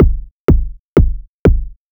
edm-kick-32.wav